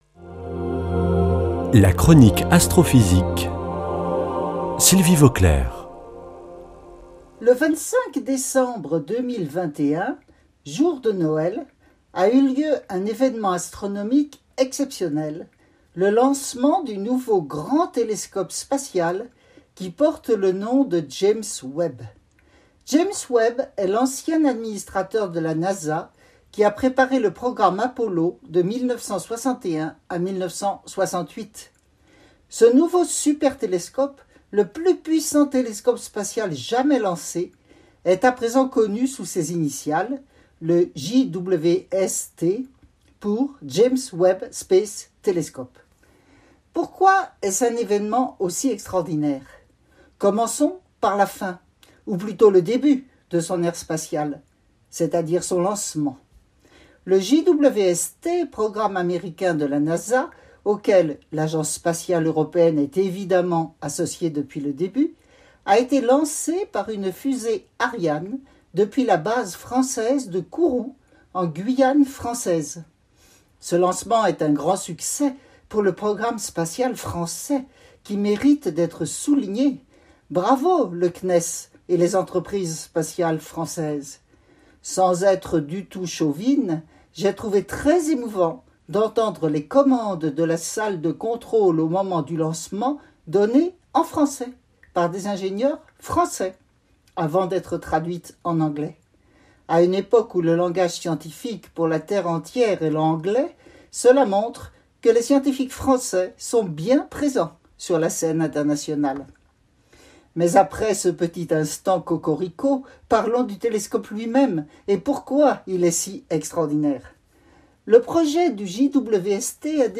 lundi 3 janvier 2022 Chronique Astrophysique Durée 3 min
Astrophysicienne